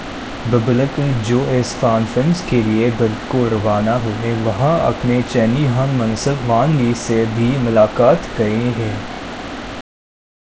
deepfake_detection_dataset_urdu / Spoofed_TTS /Speaker_07 /249.wav